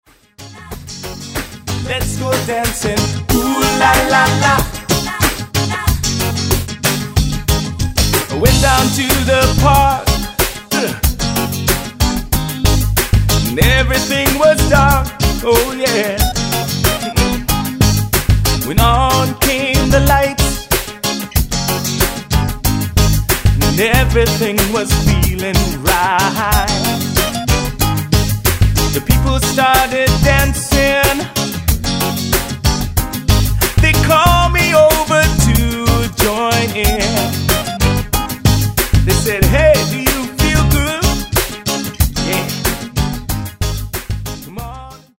smooth island style
• Genre: Contemporary pop.
Smooth vocals, crisply produced